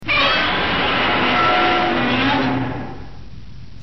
Download Godzilla sound effect for free.